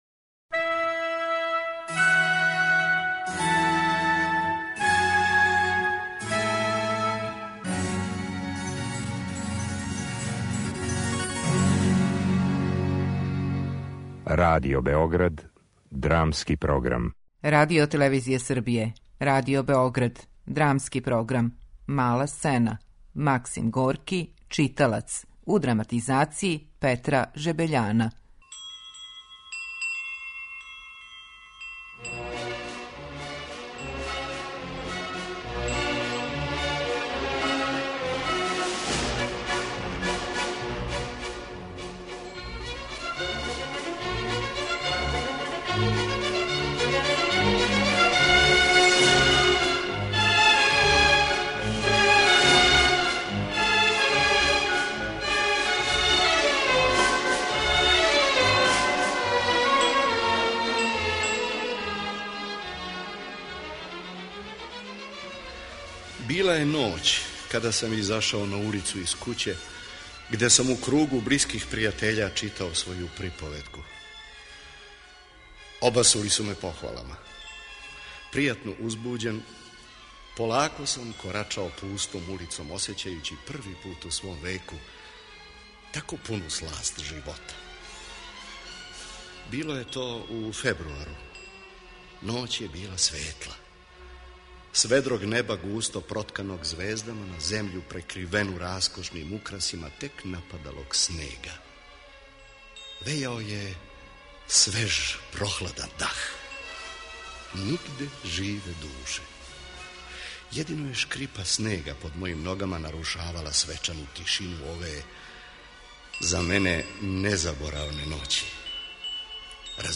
Драмски програм: Мала сцена
У радио адаптацији дела Максима Горког два лика, Писац и Читалац, у случајном сусрету на улици, проблематизују питања смисла књижевног стваралаштва.